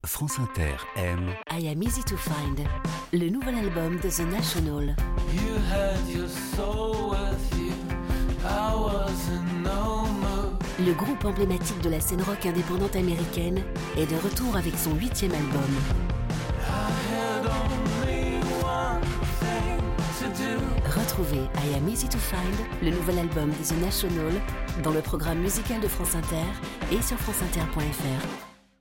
the national (naturelle balancée)